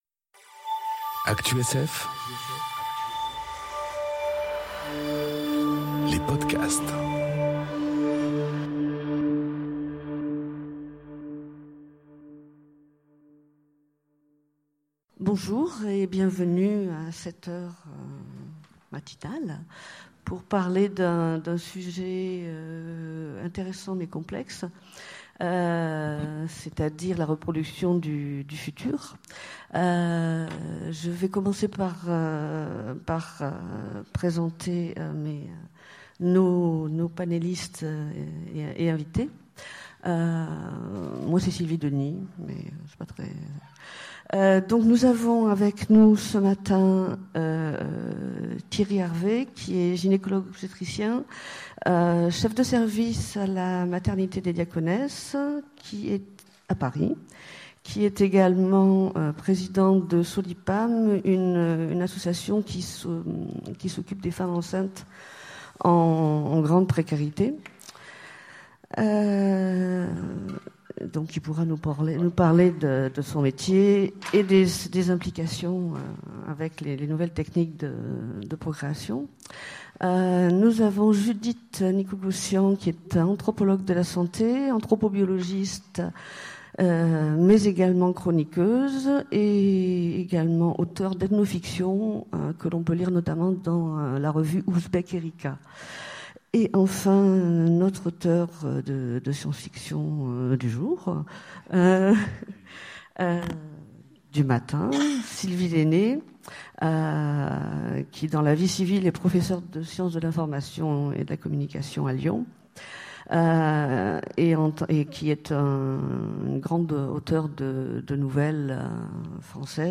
Conférence Clonage et utérus artificiel : la reproduction du futur ? enregistrée aux Utopiales 2018